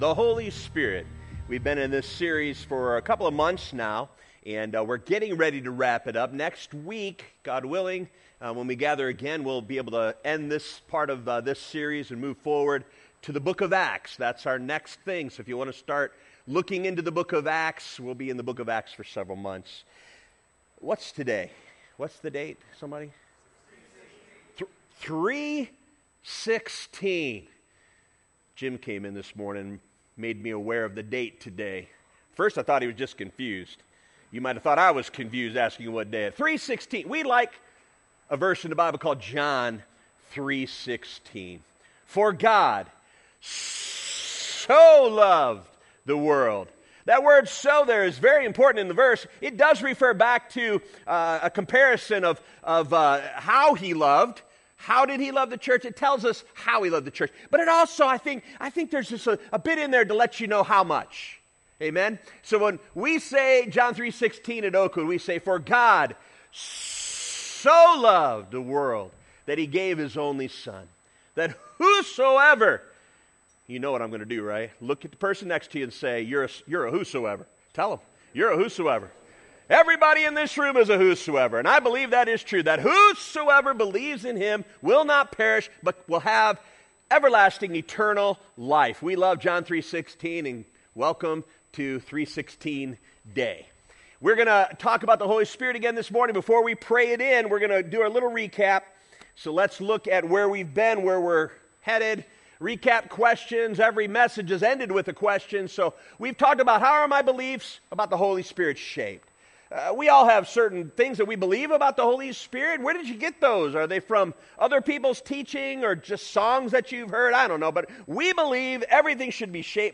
Current Sermon